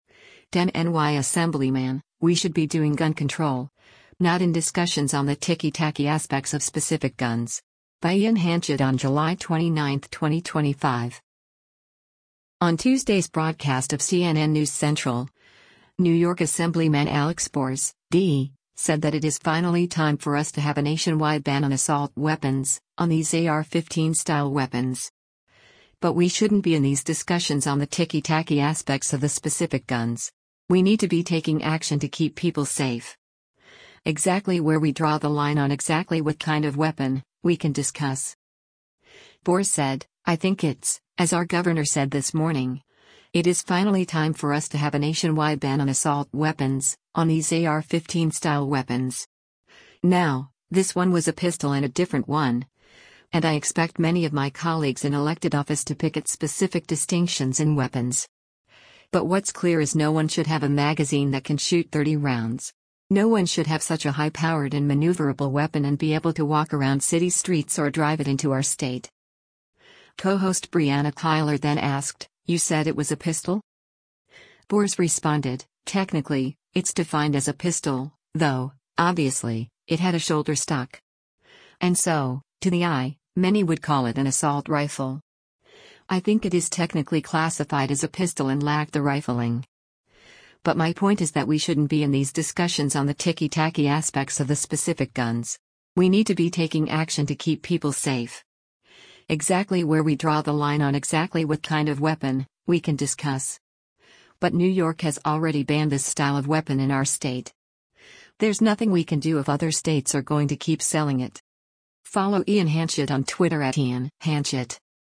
On Tuesday’s broadcast of “CNN News Central,” New York Assemblyman Alex Bores (D) said that “it is finally time for us to have a nationwide ban on assault weapons, on these AR-15-style weapons.”
Co-host Brianna Keilar then asked, “You said it was a pistol?”